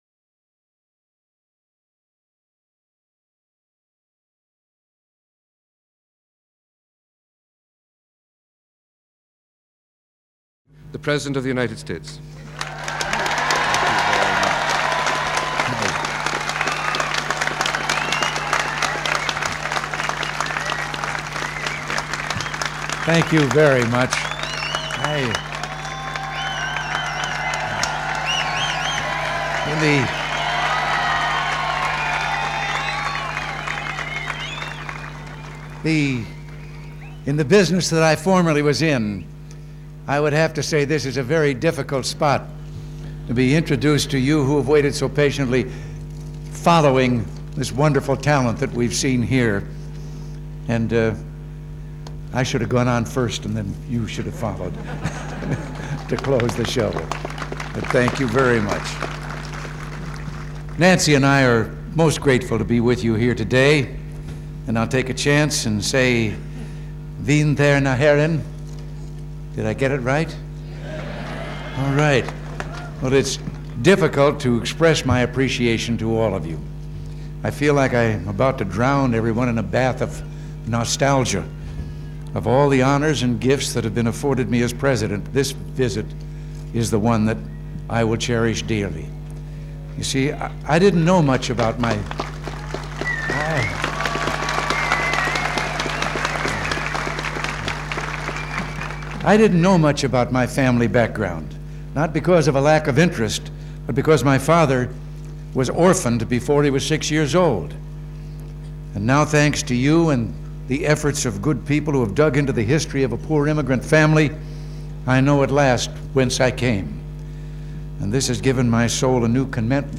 June 3, 1984: Remarks to the Citizens of Ballyporeen, Ireland